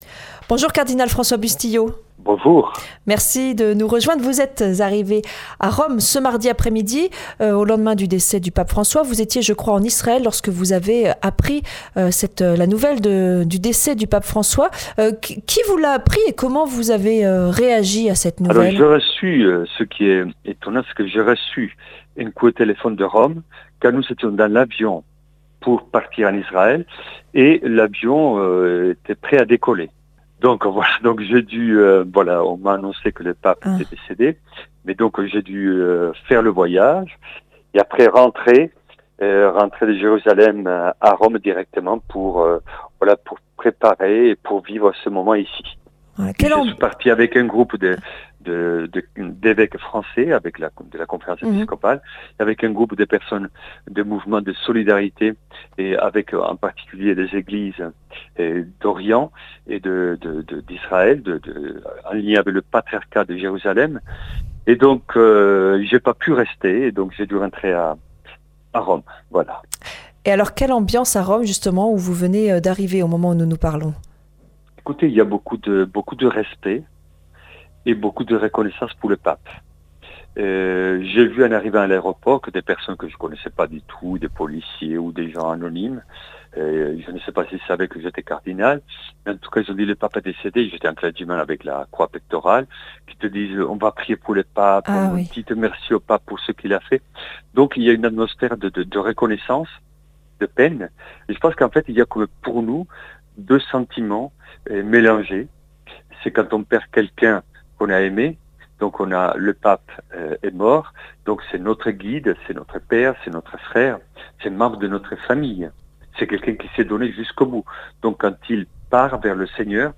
Interview exceptionnelle du cardinal François Bustillo